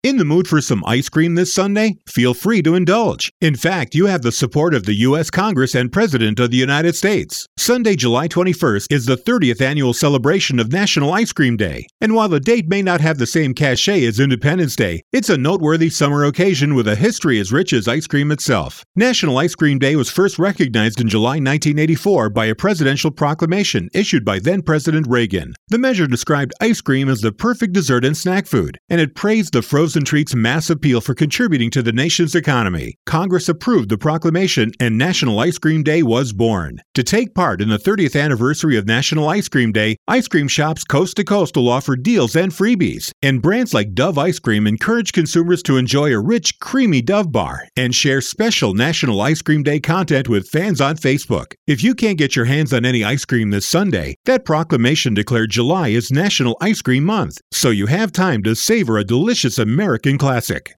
July 18, 2013Posted in: Audio News Release